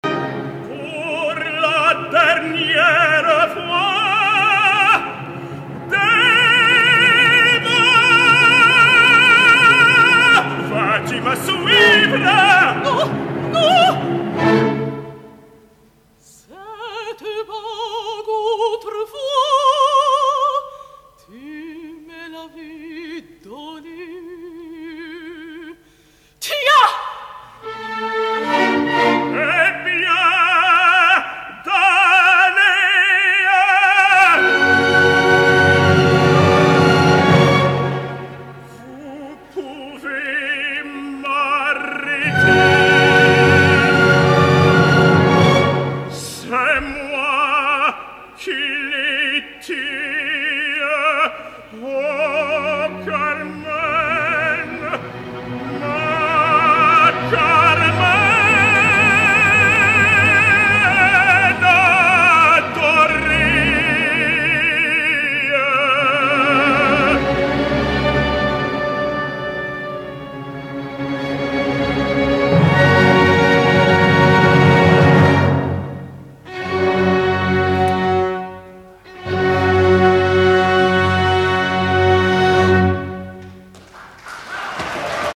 L’escoltarem en àries prou conegudes, del gran repertori i en una gravació provinent d’un concert en directa, per tant podreu apreciar les virtuts d’una veu in un cantant, de la mateixa manera que evidenciareu que no tot està assolit i que encara queden coses per polir.
mezzosoprano
on podreu apreciar aquesta veu amb tonalitats bronzines que jo crec que el situa en una vocalitat més heroica que no pas lírica.
Tots els fragments provenen d’un concert celebrat el dia 9 de gener de 2013 a Jerusalem, l’orquestra està dirigida per Frédéric Chaslin